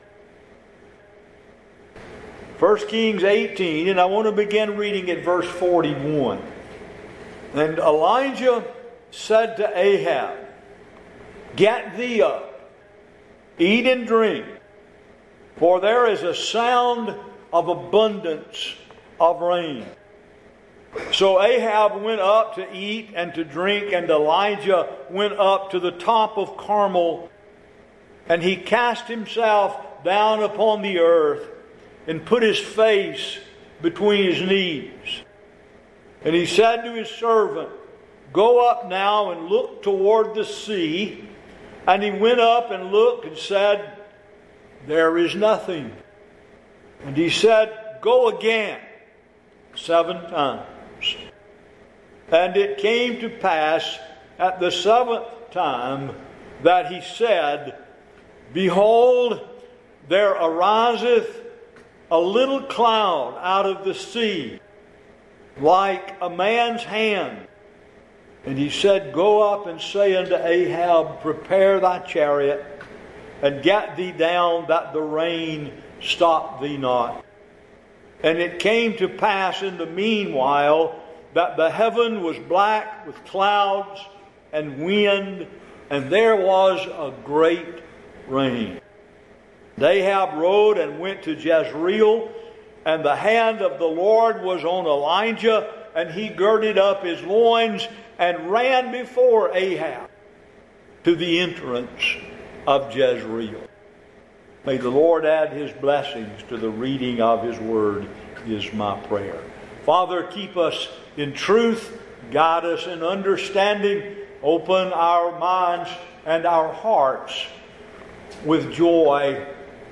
1 Kings 18:41-46, An Abundance Of Rain Feb 7 In: Sermon by Speaker